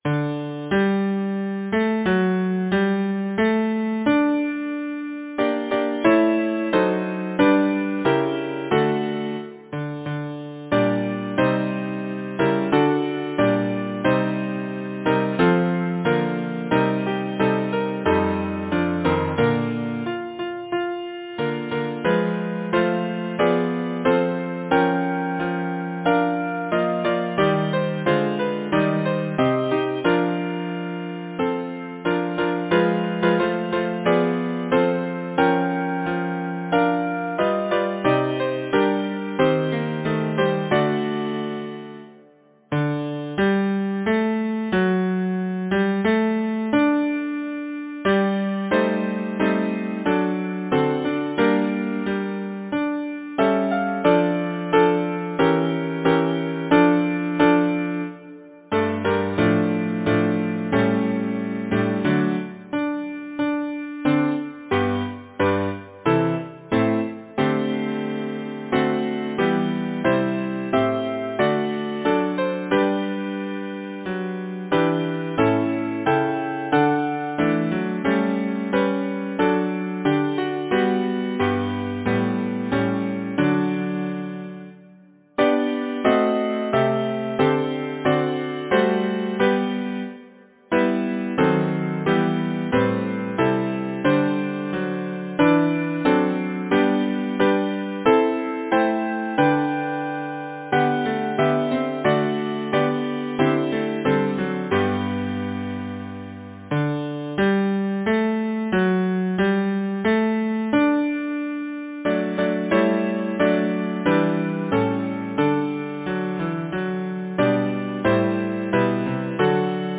Title: The Village Choir Composer: Edward Cutler Lyricist: Number of voices: 4vv Voicing: SATB Genre: Secular, Partsong
Language: English Instruments: A cappella